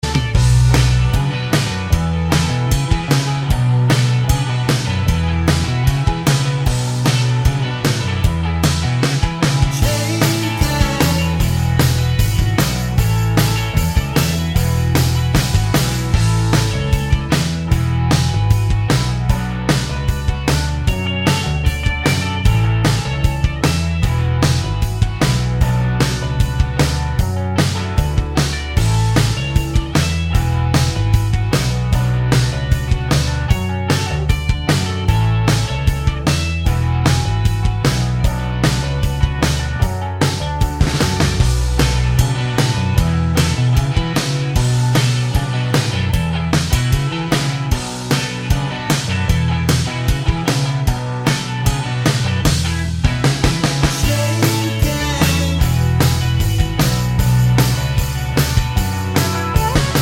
no Backing Vocals Indie / Alternative 3:38 Buy £1.50